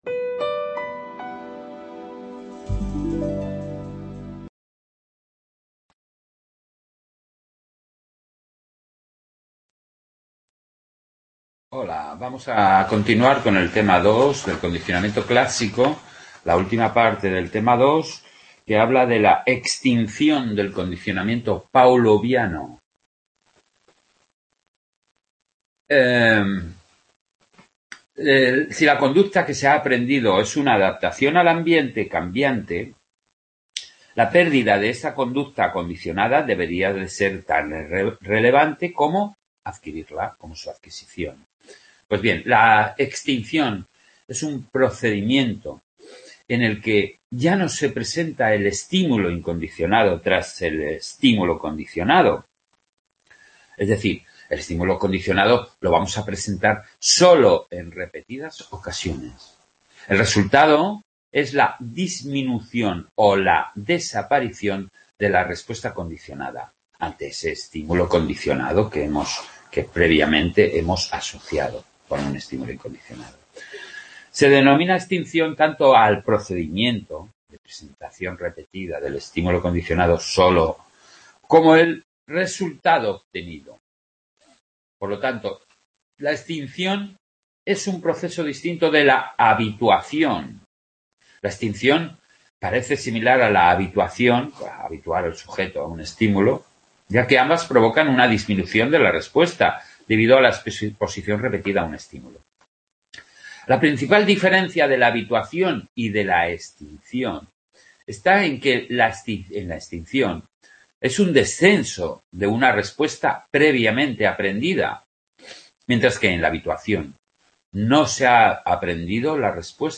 Tema 2, final: CC, extinción, de Psicología del Aprendizaje,, grabación realizada en el Centro Asociado de Sant Boi